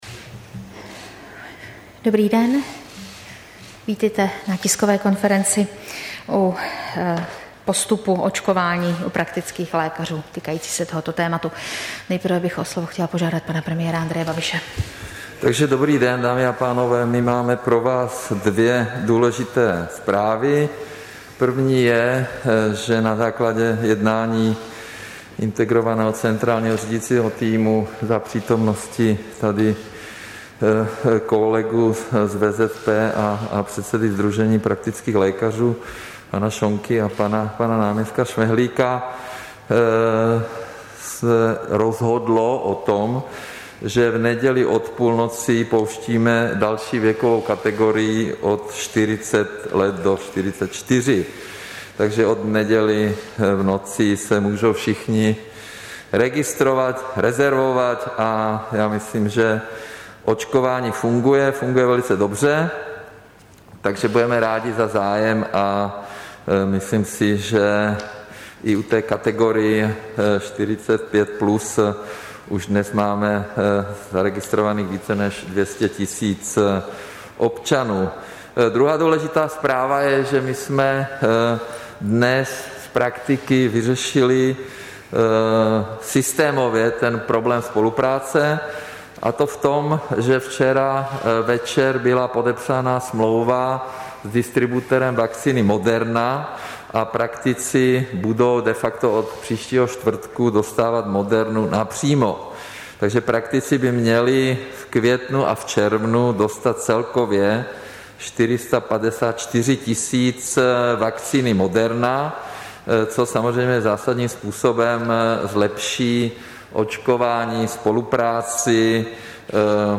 Tisková konference k postupu očkování u praktiků, 13. května 2021